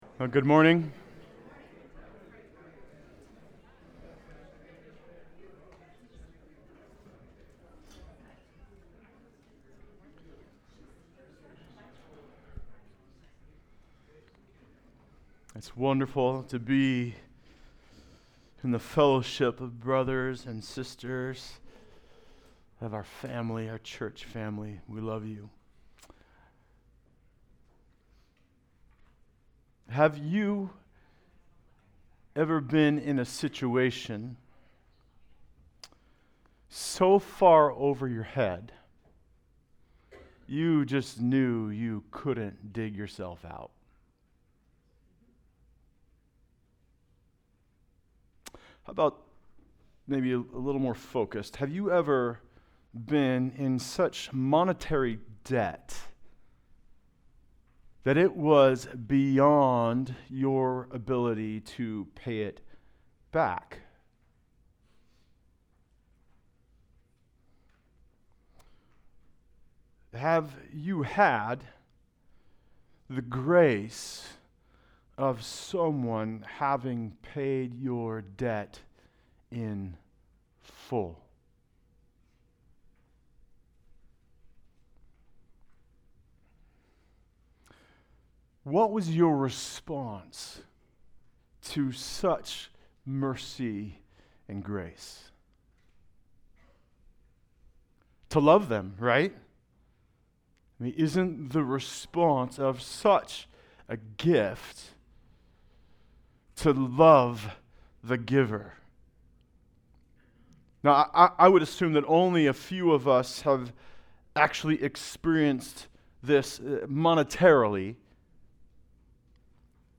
Passage: Luke 7:36-50 Service Type: Sunday Service